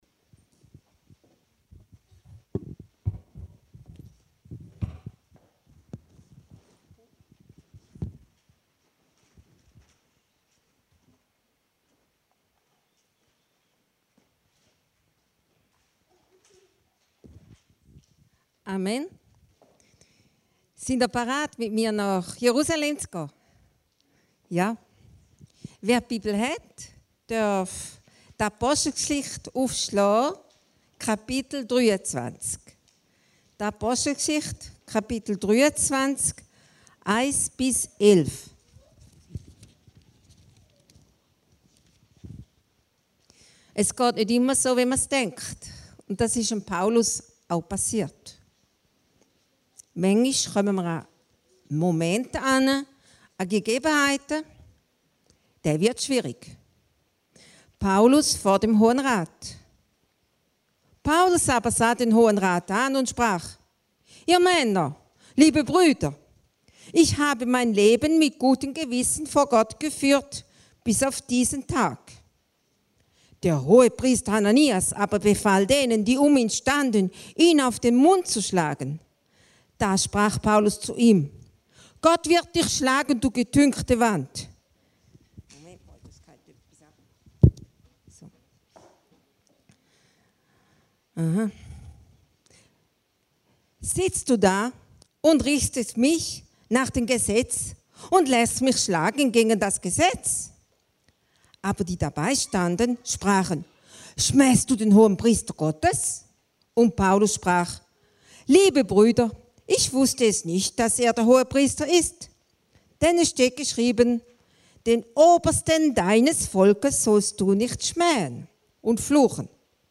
Predigten der Heilsarmee Aargau Süd